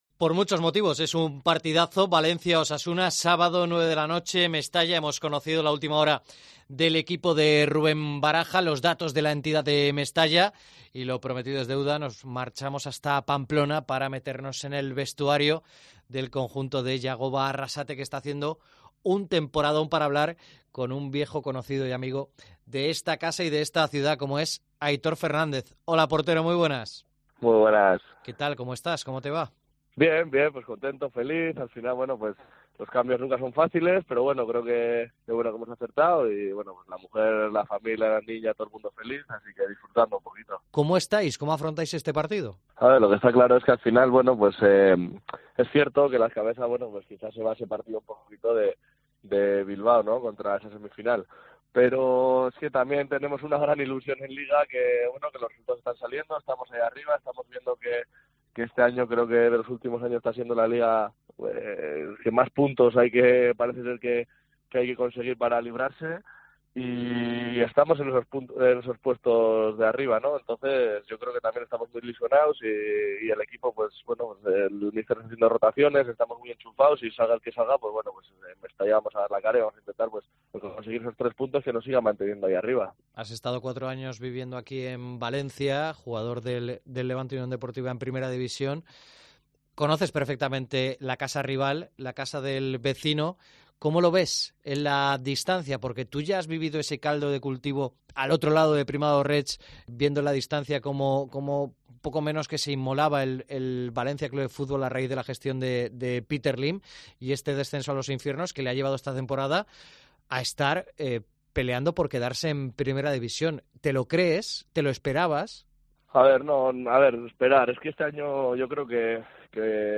Entrevista en COPE a Aitor, portero de CA Osasuna